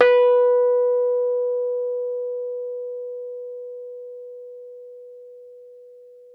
RHODES CL0EL.wav